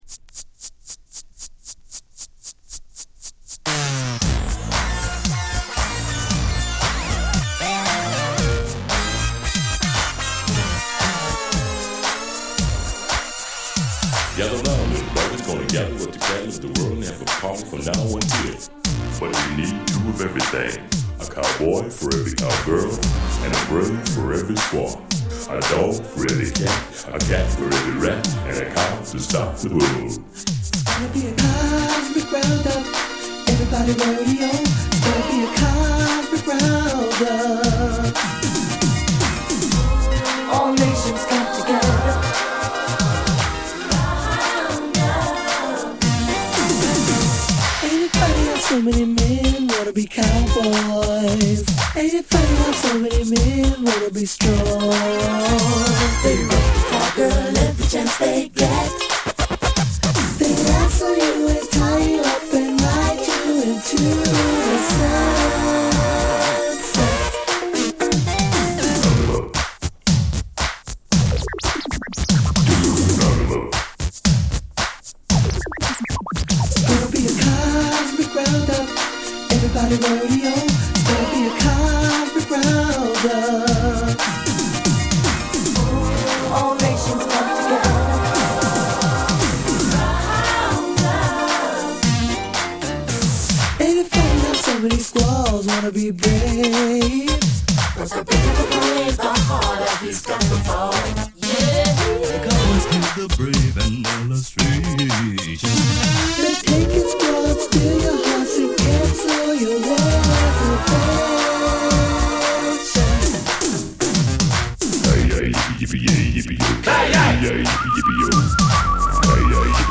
派手目なエレドラやシンセを全面にfeat.したエレクトリックなファンクを披露！